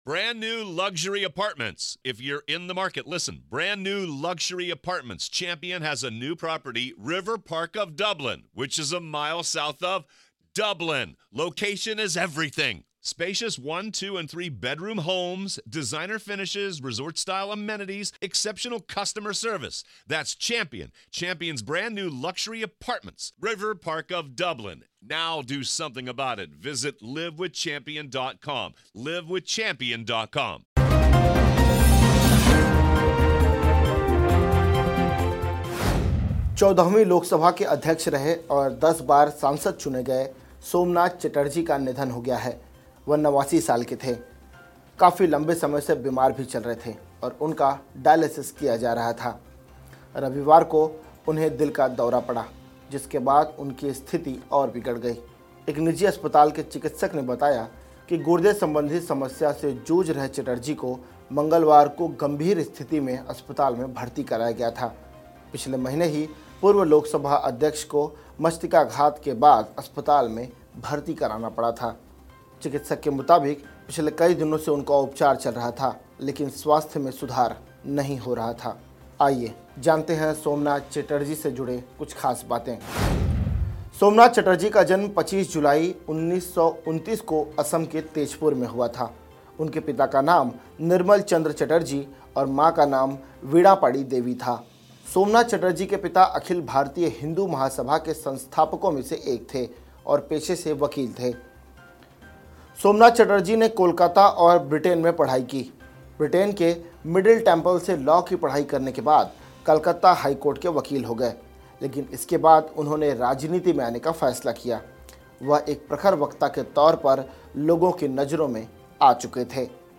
न्यूज़ रिपोर्ट - News Report Hindi / हिंदूवादी पिता का कम्युनिस्ट पुत्र, जब सीपीएम ने सोमनाथ चटर्जी को पार्टी से निकाला